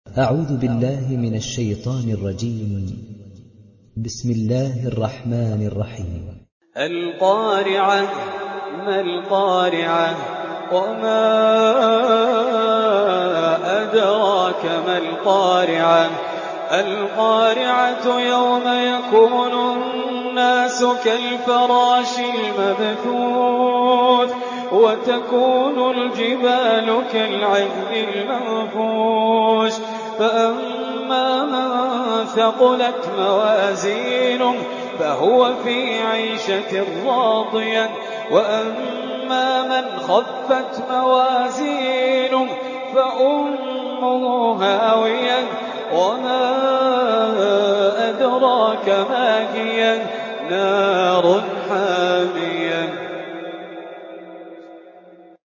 تحميل سورة القارعة mp3 بصوت خالد الجليل برواية حفص عن عاصم, تحميل استماع القرآن الكريم على الجوال mp3 كاملا بروابط مباشرة وسريعة